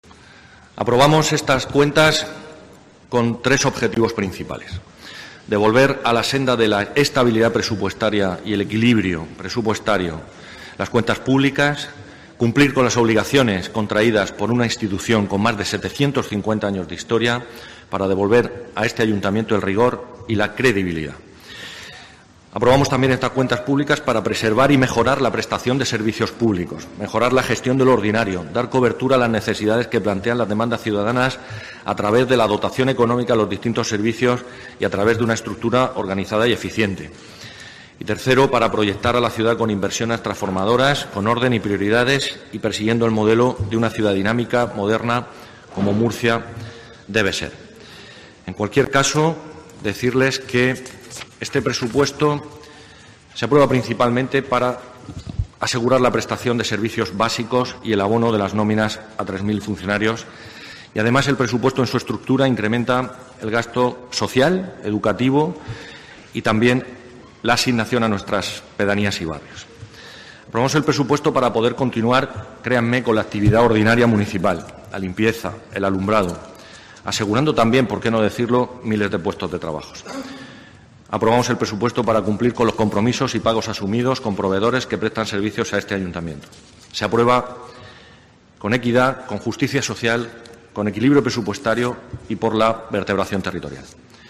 José Francisco Muñoz, concejal de Movilidad, Gestión Económica y Contratación